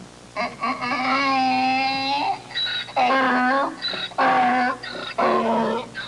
Donkey Sound Effect
Download a high-quality donkey sound effect.
donkey-1.mp3